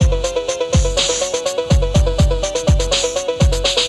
187 In Progress Melody.wav